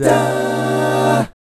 1-CMI7  AA-L.wav